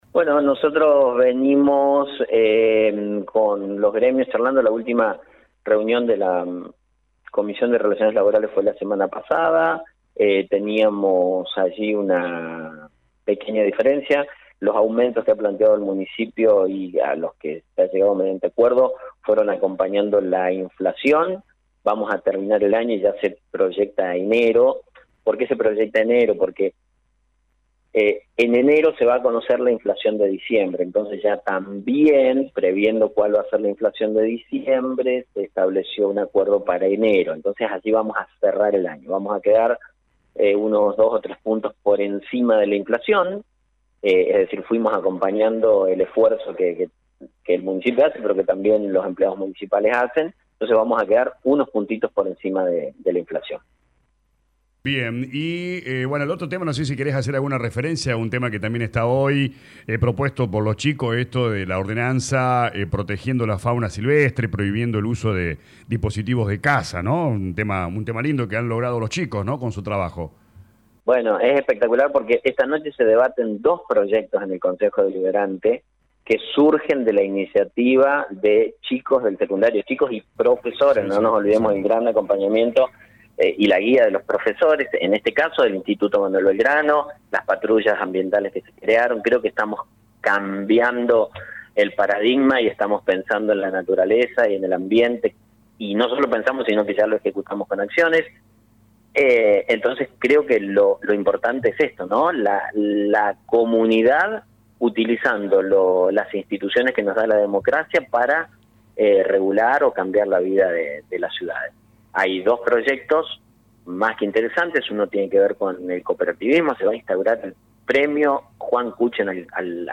En diálogo con LA RADIO el intendente Actis manifestó que en las últimas semanas mantuvo reuniones con la comisión de relaciones laborales para definir la meta salarial.